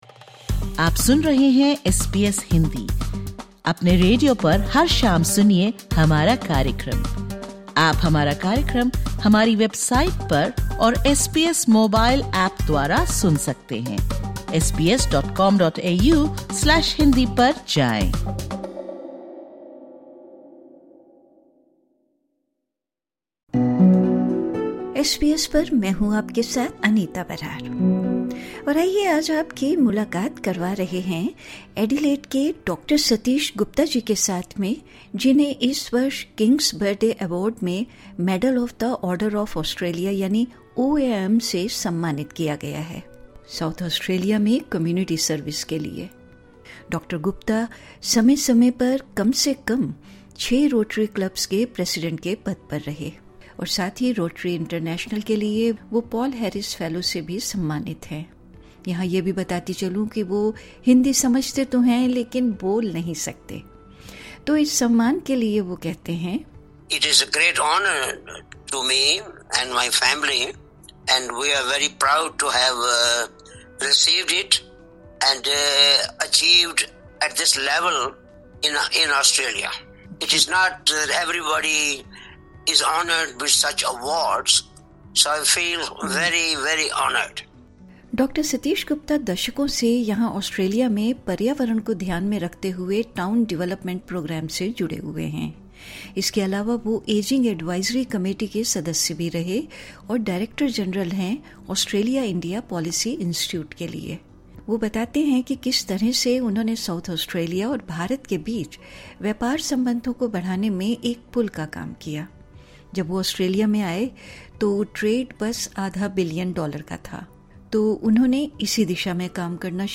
In an interview with SBS Hindi, he discussed his writing journey and how his initiatives have fostered strong trade relations between South Australia and India.